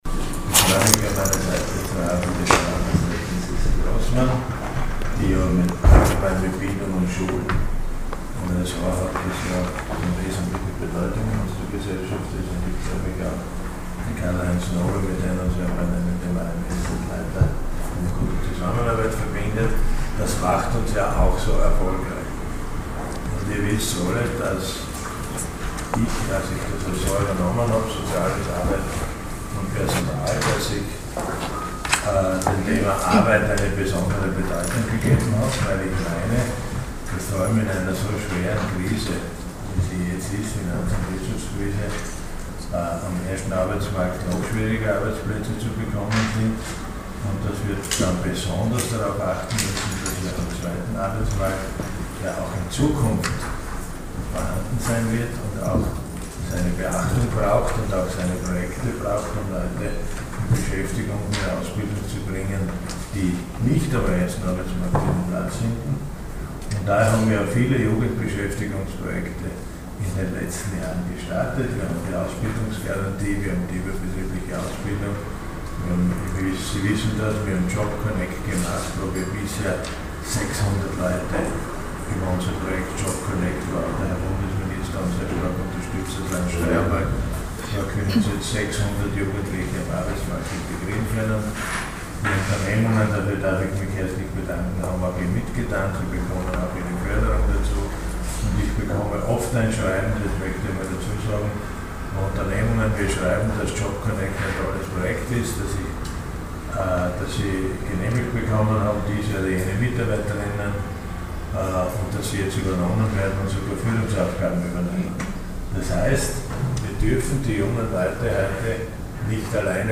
Öffnet ein neues Fenster: O-Ton
Graz (9. Juni 2010).- Heute Vormittag präsentierte LH-Stv. Siegfried Schrittwieser gemeinsam mit Landesrätin Elisabeth Grossmann und AMS-Chef Karl Heinz Snobe im Medienzentrum Steiermark "c'mon14", das neue Berufsinformationsangebot für Schülerinnen und Schüler von 14 bis 17 Jahren.